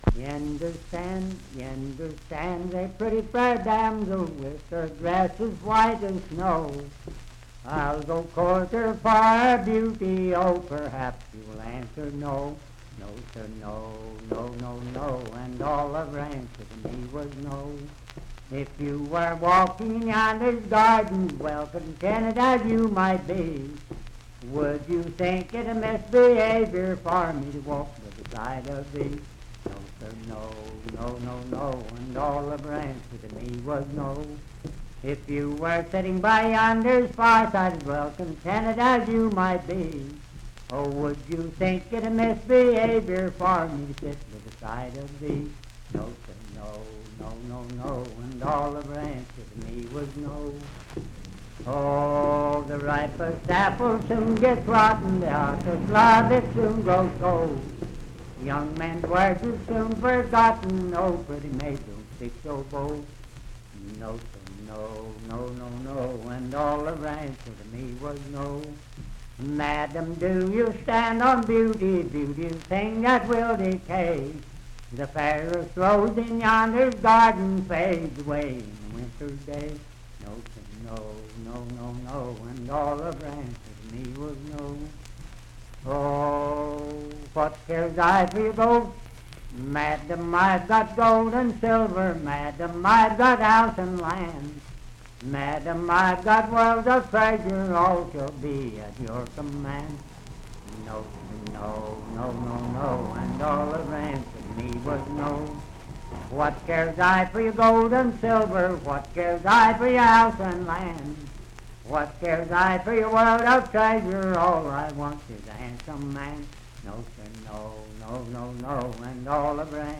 Unaccompanied vocal music and folktales
Dance, Game, and Party Songs
Voice (sung)
Parkersburg (W. Va.), Wood County (W. Va.)